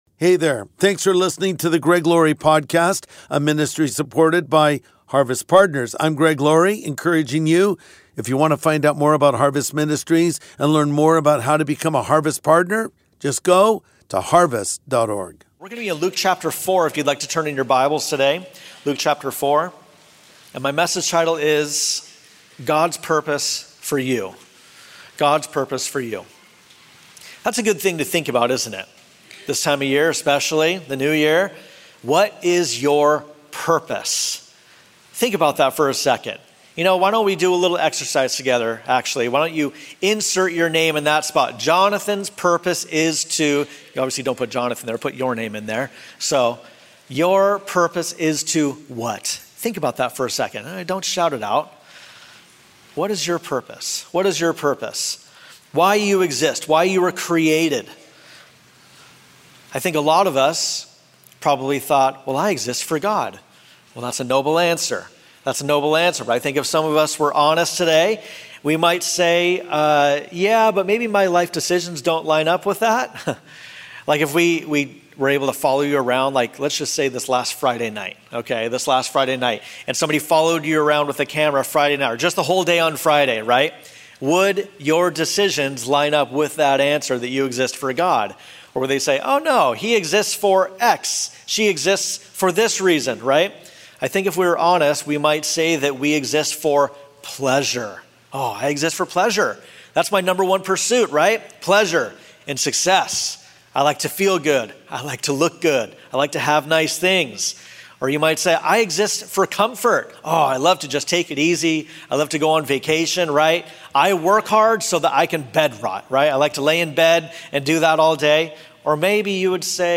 God’s Purpose for You | Sunday Message